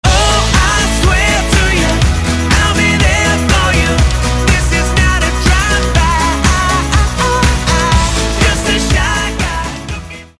GenrePoprock